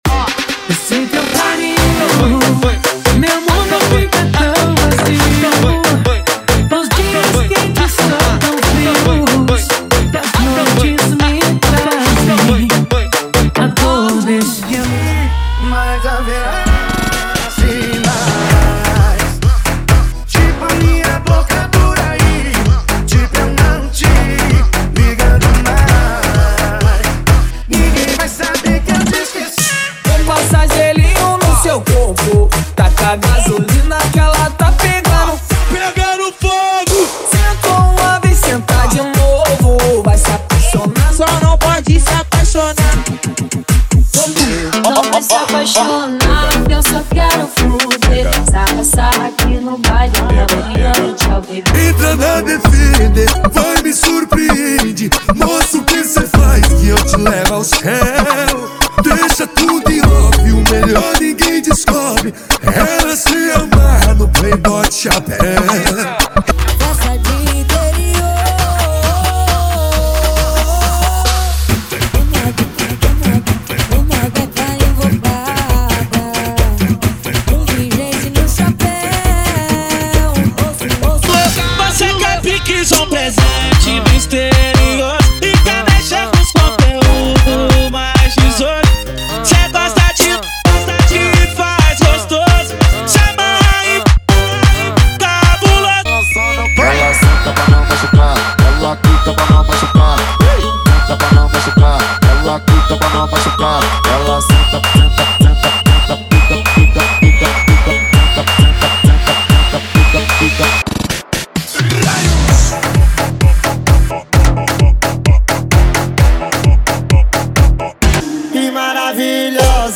• Funk Light e Funk Remix = 100 Músicas
• Sem Vinhetas